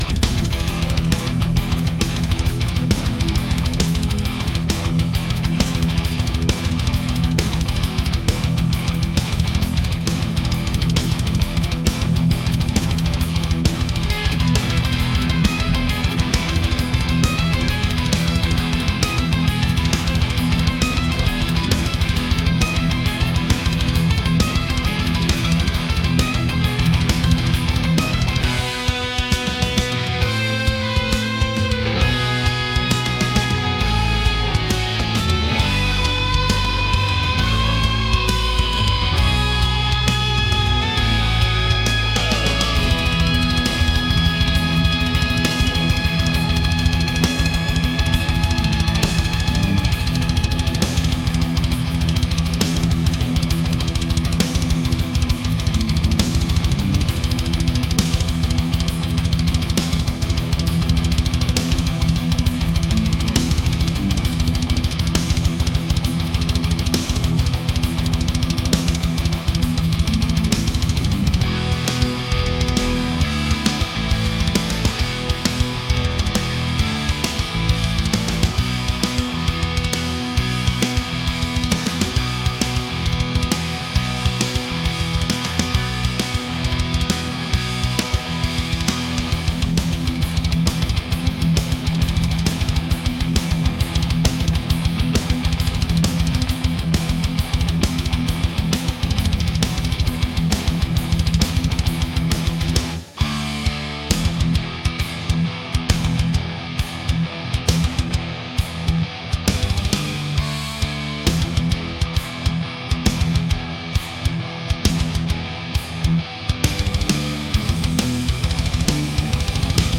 intense | metal